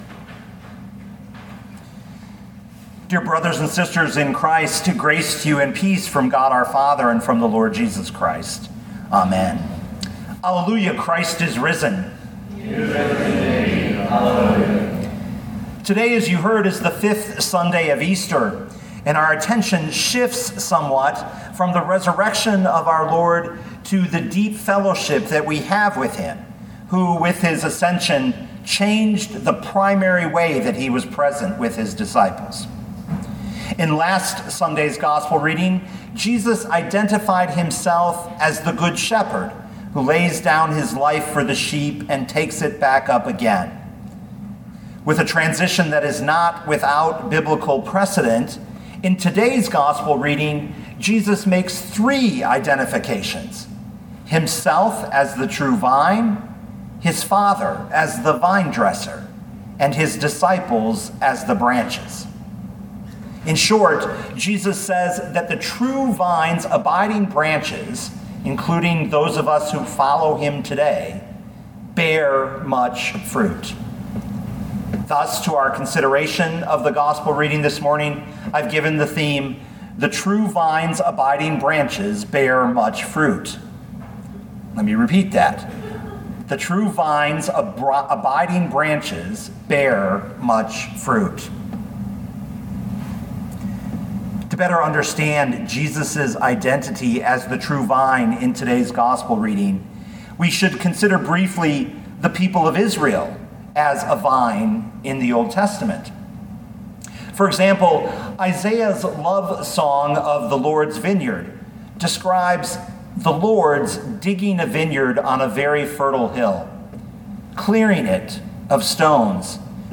2021 John 15:1-8 Listen to the sermon with the player below, or, download the audio.